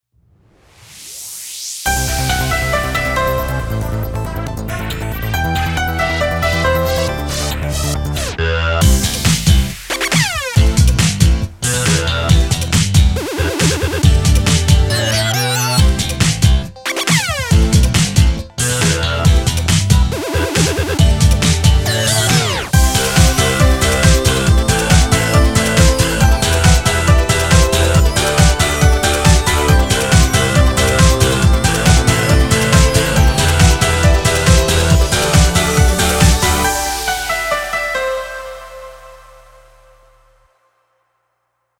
seven alarm